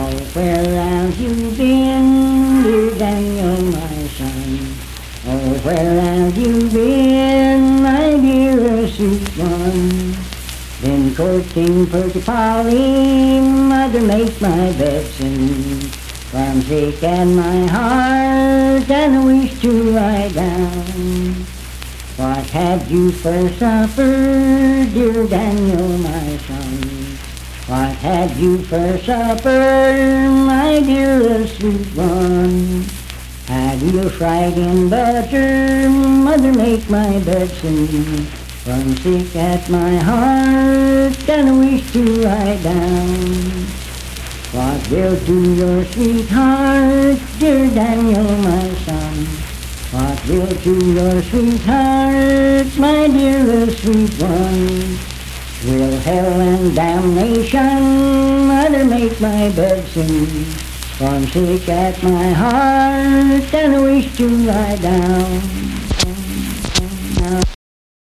Unaccompanied vocal music
Verse-refrain 3d(4w/R).
Performed in Sandyville, Jackson County, WV.
Voice (sung)